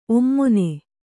♪ ommone